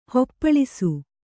♪ hoppaḷisu